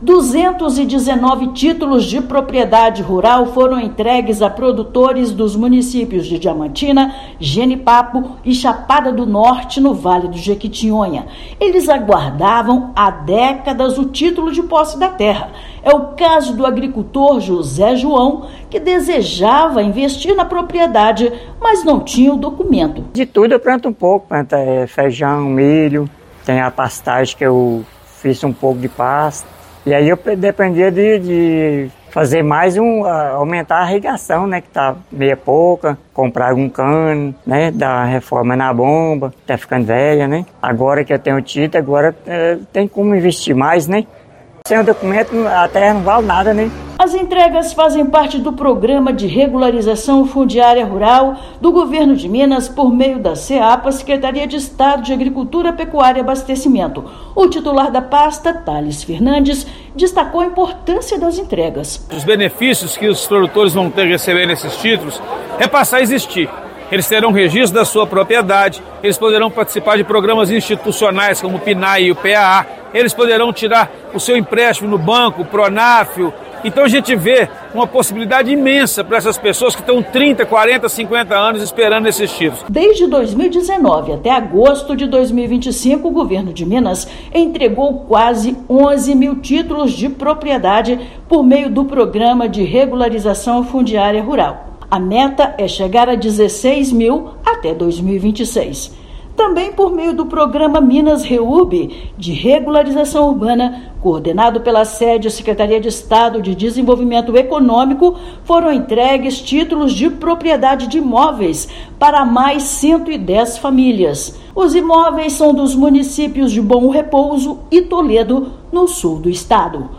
Além da entrega de 219 documentos de posse da terra, no Vale do Jequitinhonha, também foram entregues mais 110 títulos de imóveis urbanos, no Sul de Minas. Ouça matéria de rádio.